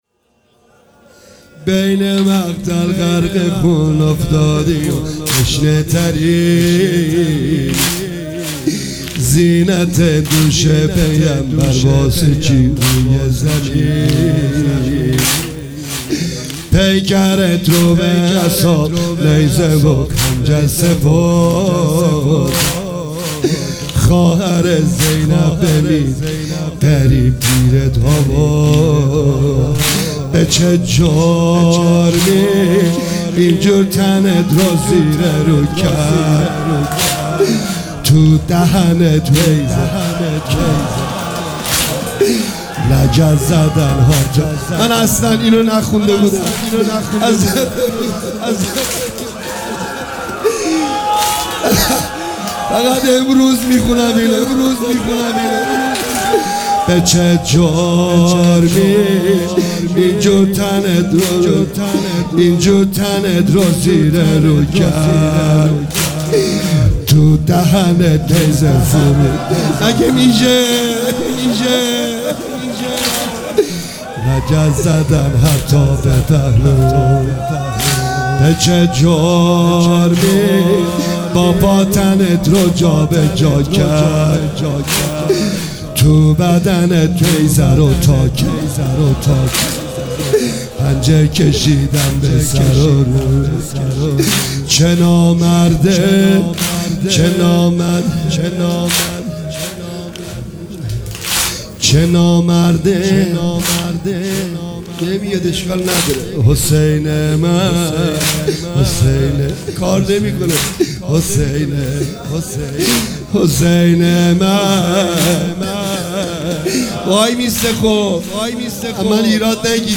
روز عاشورا 1404